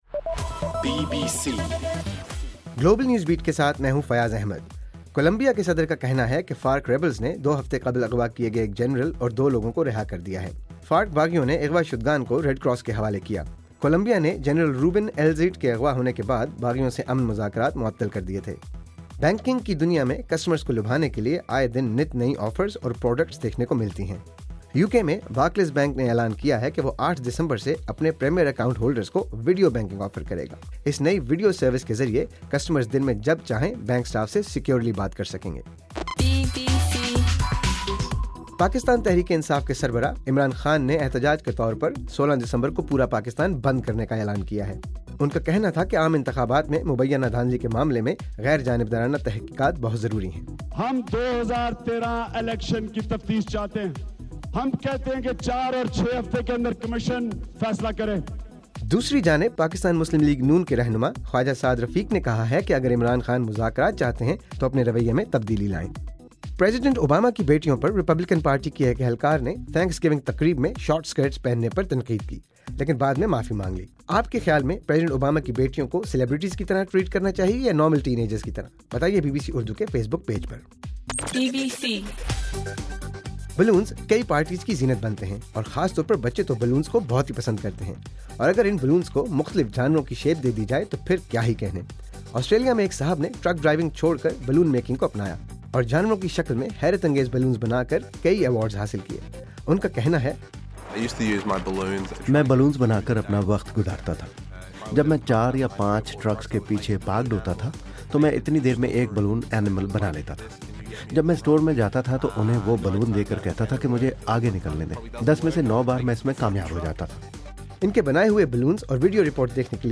نومبر 30: رات 12 بجے کا گلوبل نیوز بیٹ بُلیٹن